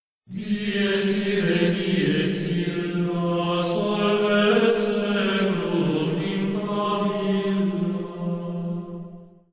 dies-irae-chant